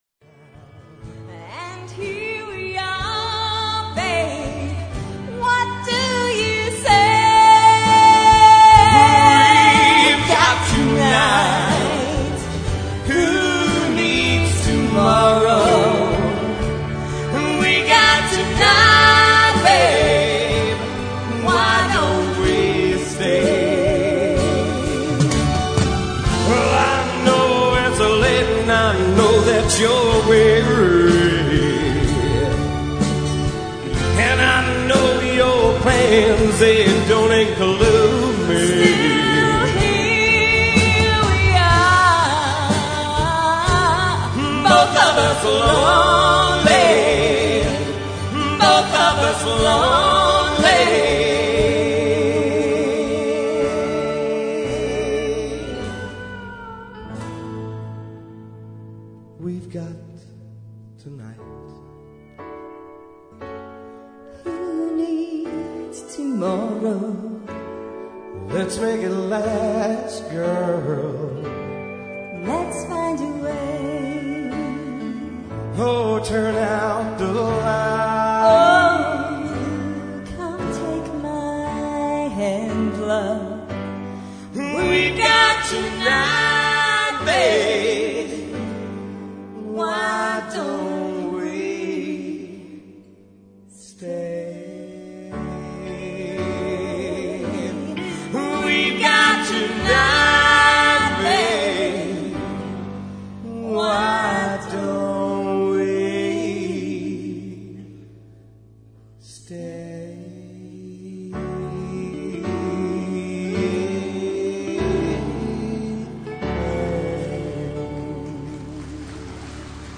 in a duet